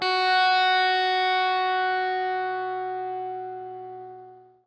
SPOOKY    AP.wav